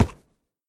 sounds / mob / horse / wood6.mp3
wood6.mp3